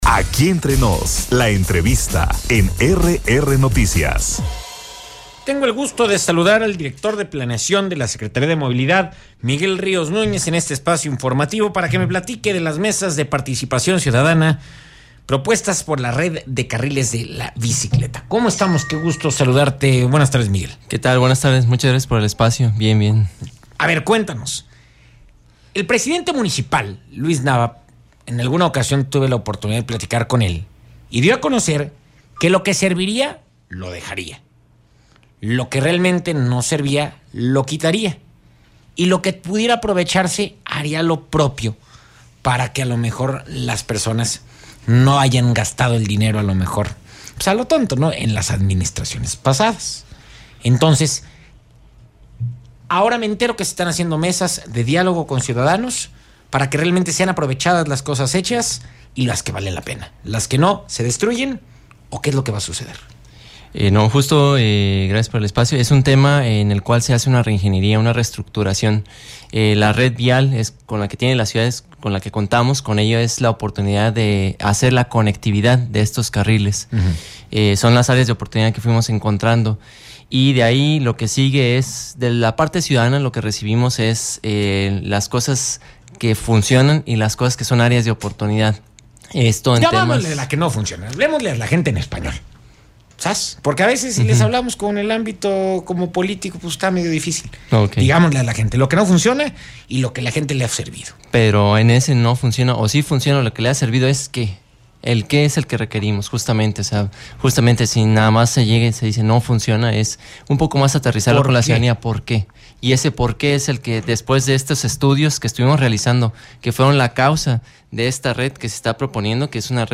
ENTREVISTA-DIR-DE-PLANEACION-PARA-LA-MOVILIDAD-MIGUEL-RIOS-NUÑEZ.mp3